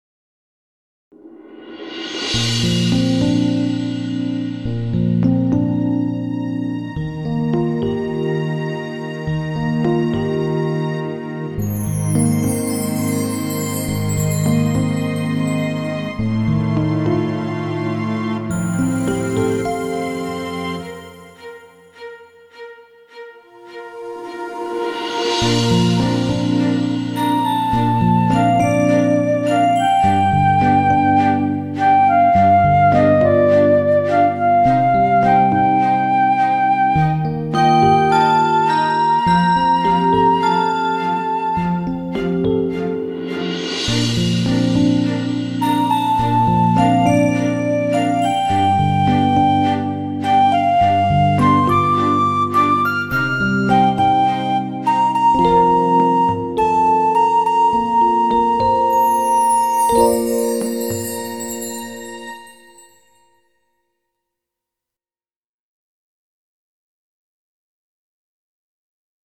インストゥルメンタル曲